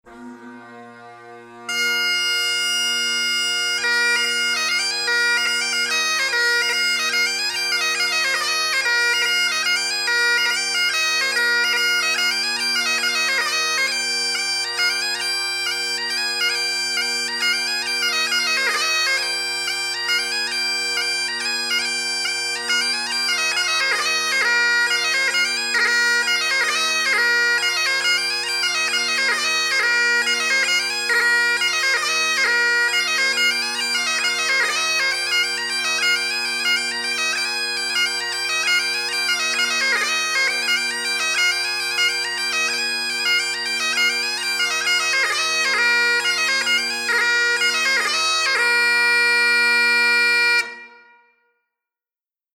Scottish and Irish Bagpipe Music
Paddy Be Easy – Jig